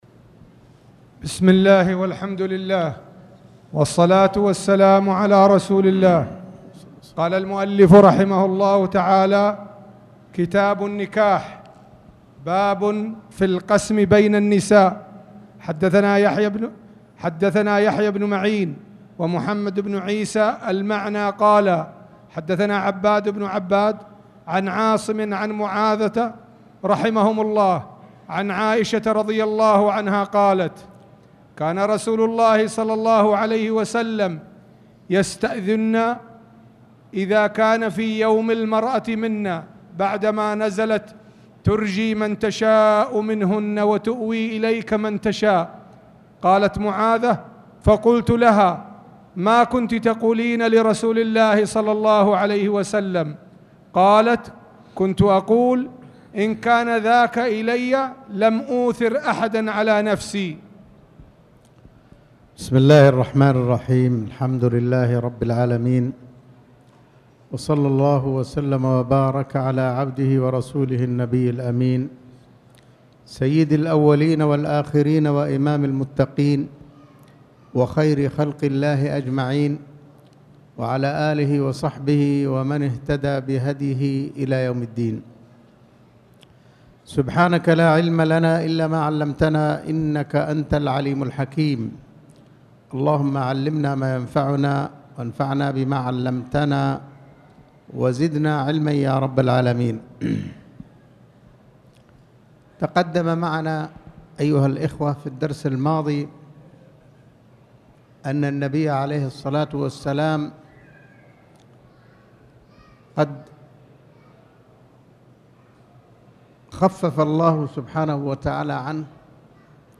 تاريخ النشر ٢٦ صفر ١٤٣٨ هـ المكان: المسجد الحرام الشيخ